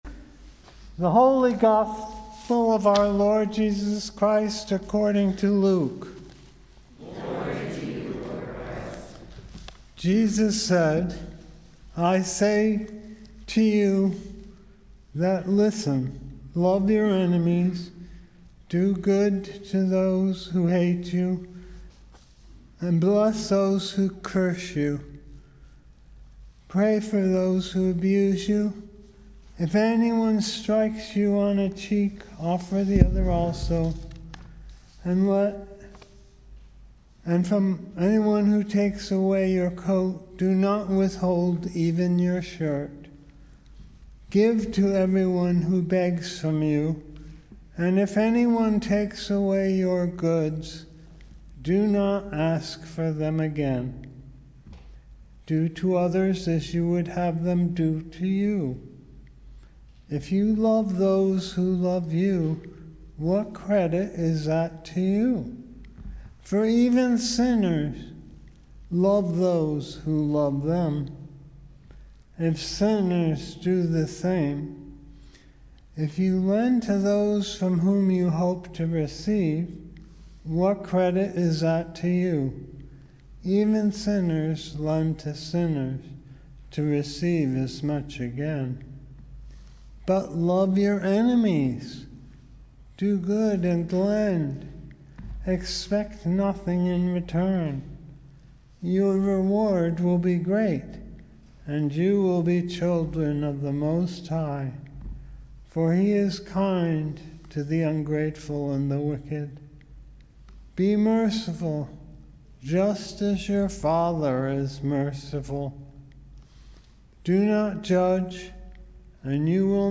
Gospel & Sermon, February 20, 2022 - St. Andrew's Episcopal Church